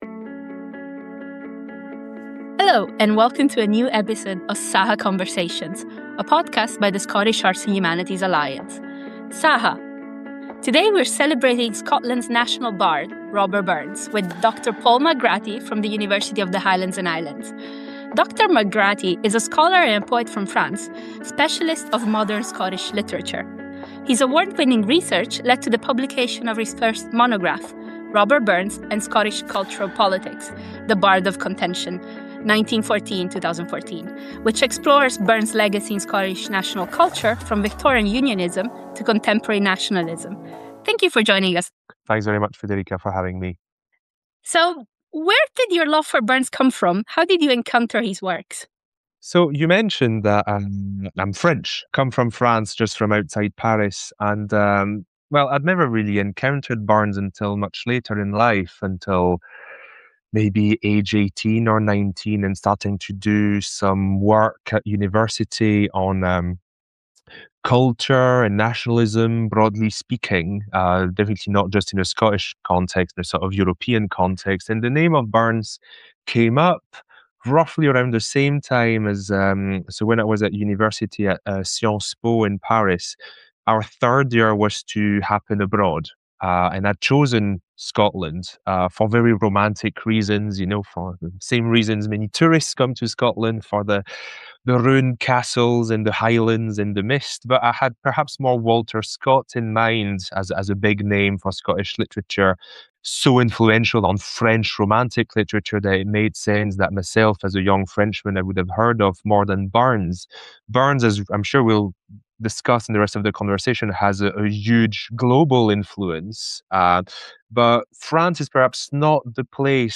SAHA Conversations A SAHA Conversation